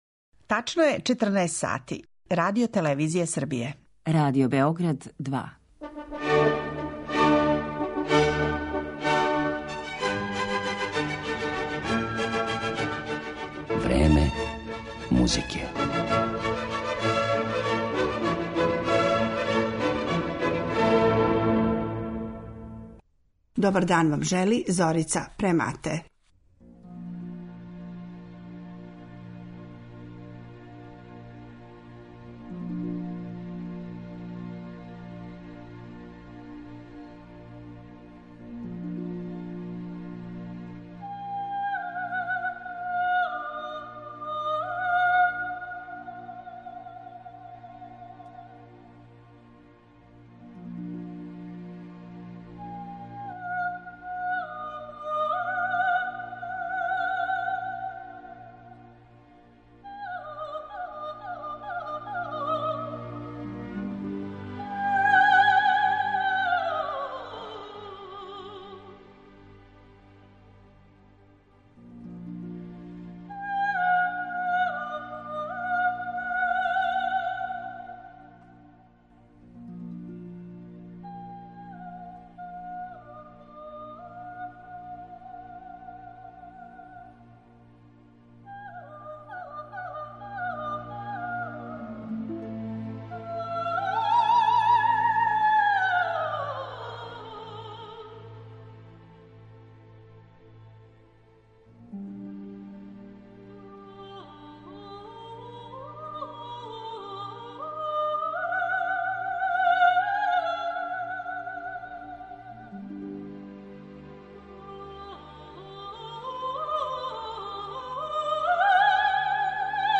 У музици је уобичајено да се тонски дочаравају разни звуци из природе, а једна од најомиљенијих тема у том подручју је - певање птица, поготово славуја.